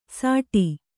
♪ sāṭi